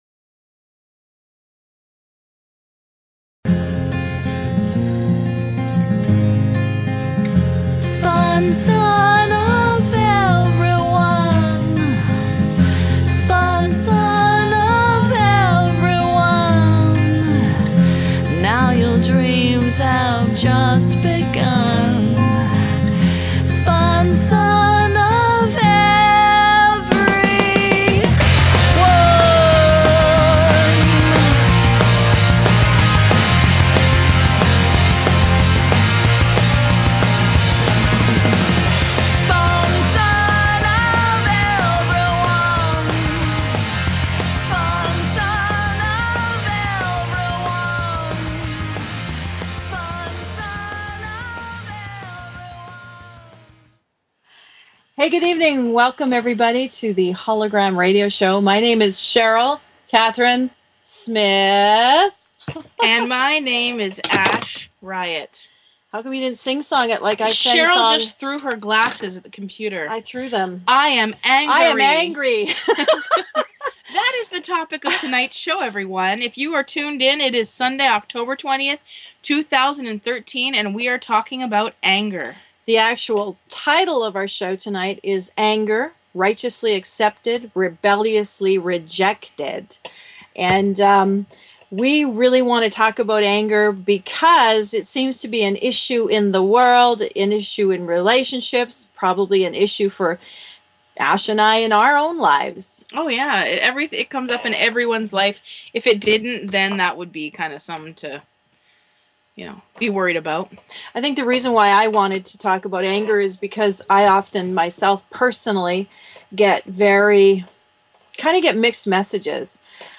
Talk Show Episode, Audio Podcast, Hollow-Gram_Radio_Show and Courtesy of BBS Radio on , show guests , about , categorized as
They offer a unique radio show where they share their music, their sharp wit, and their visions for This planet.